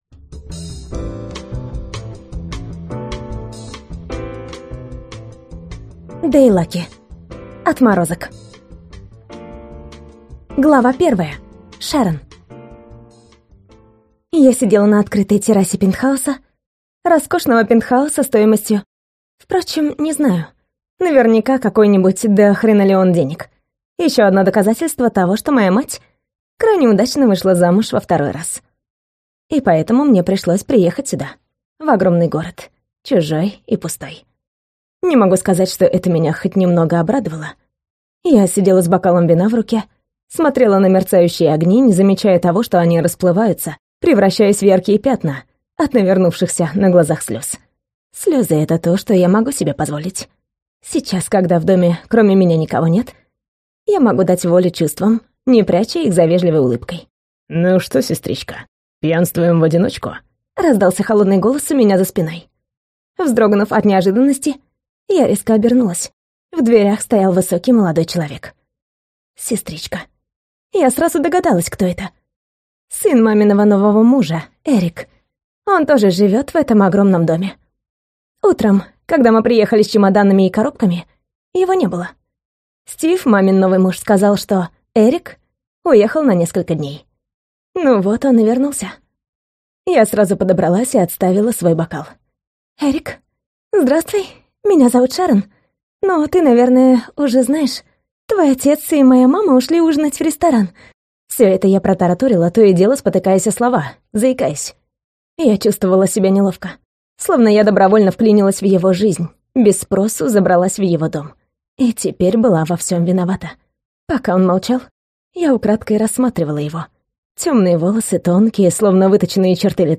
Аудиокнига Отморозок | Библиотека аудиокниг